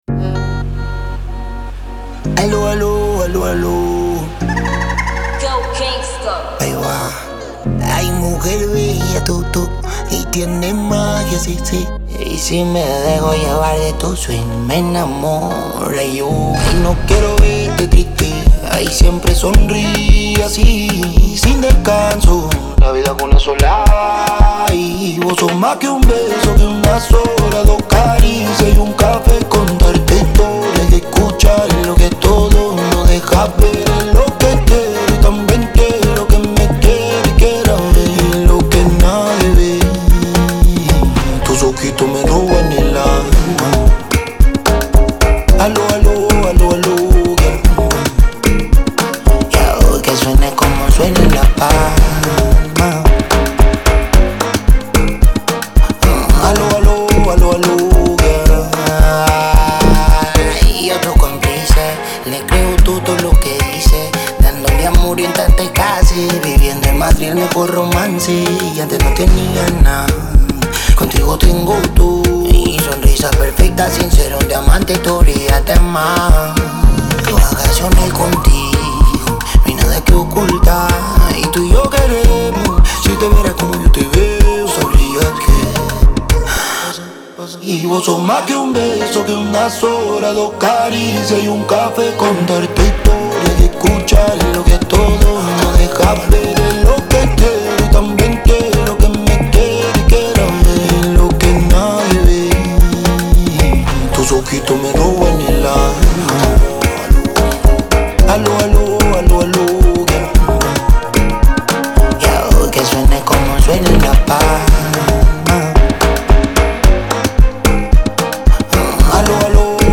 12/100 Genres : french rap, pop urbaine Télécharger